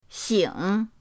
xing3.mp3